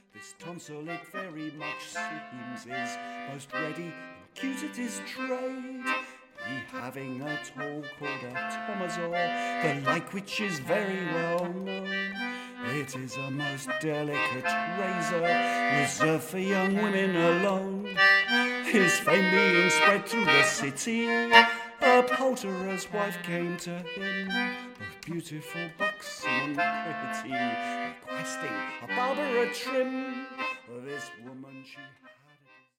recorders, voice and English Concertina
Anglo Concertinas and voice